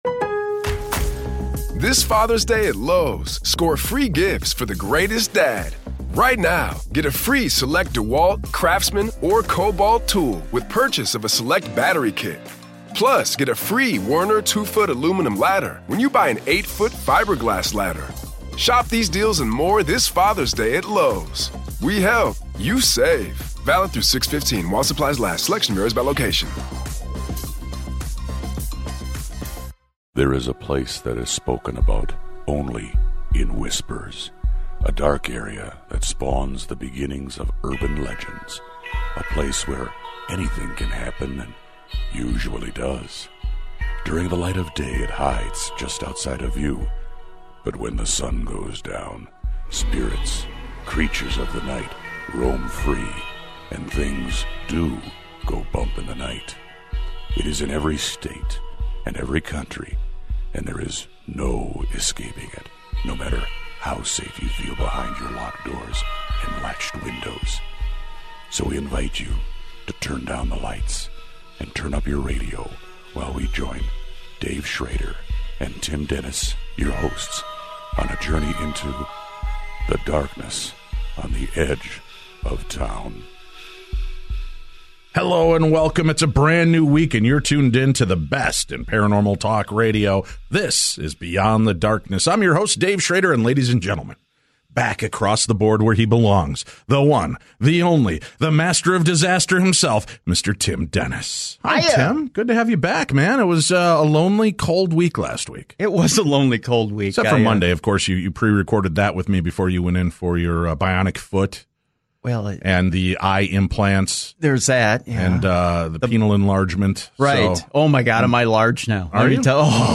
All the strange and supernatural news from around the world for the week that was, and the boys talk about their visit to Paisley Park and review Skull Island:Kong and Logan!!